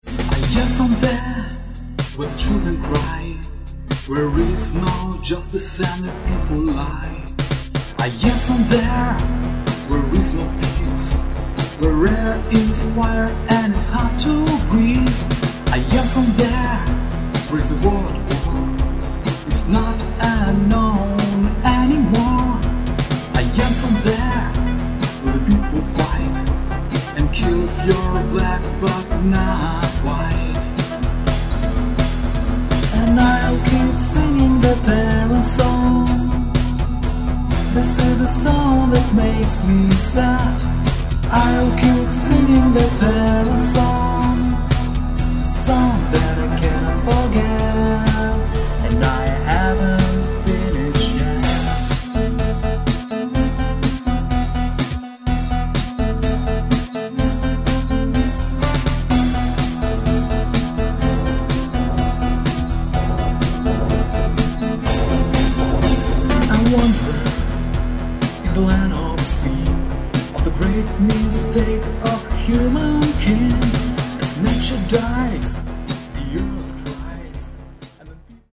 Песенные композиции: